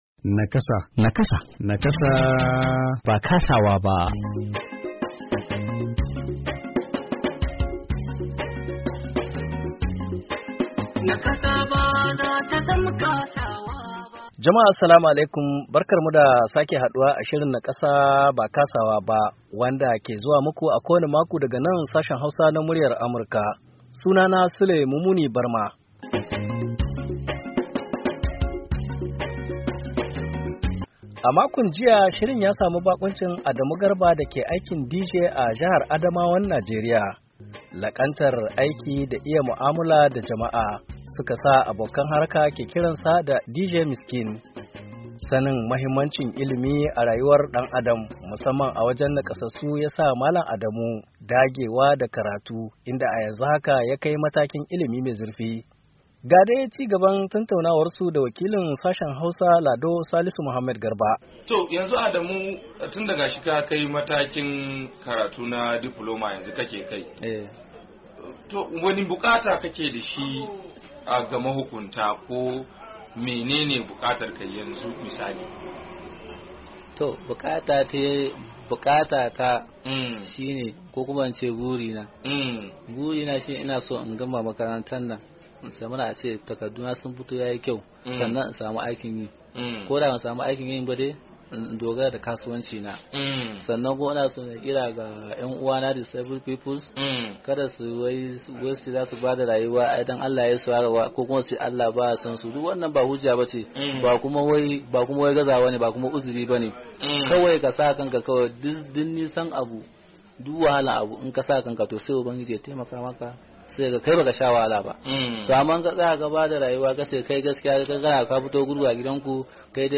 Shirin Nakasa na wannan makon, cigaban tattauna ne da wani mai nakasa mai sana’ar DJ da ke jihar Adamawan Najeriya.